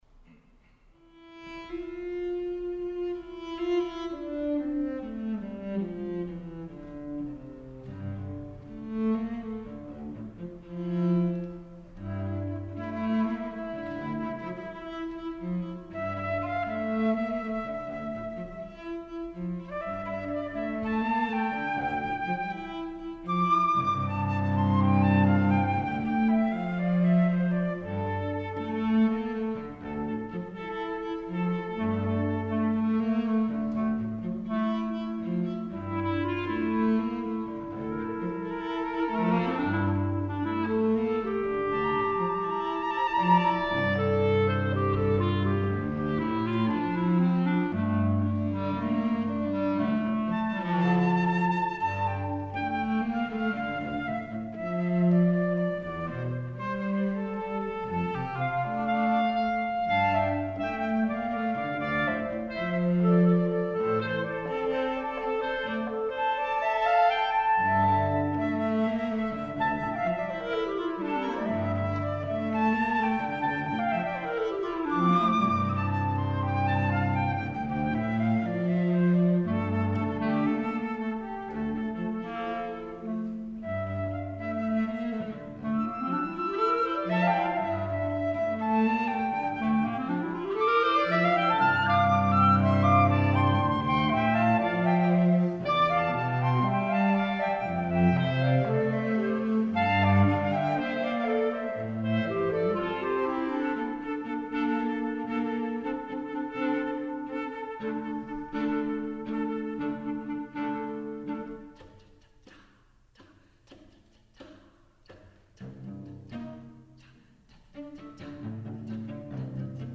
for Flute, Clarinet, and Cello (2016)
The music ends with players drawn together in semitones.